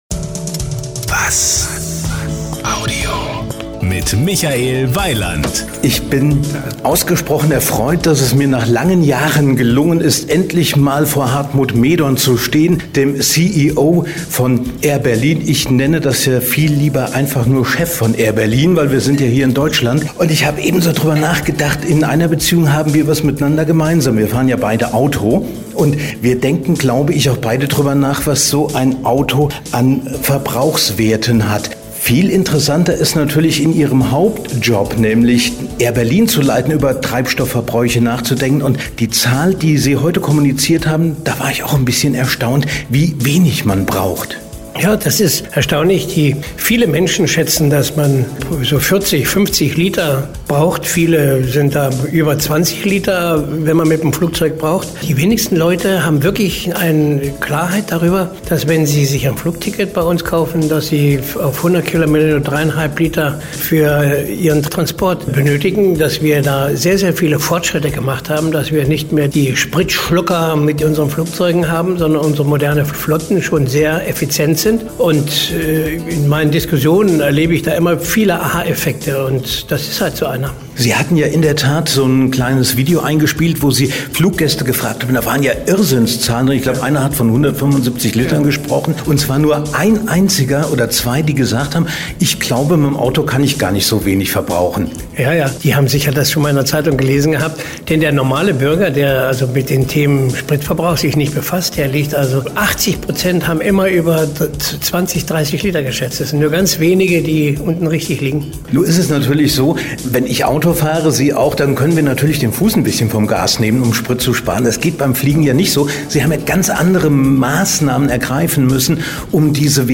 Interview mit airberlin CEO Hartmut Mehdorn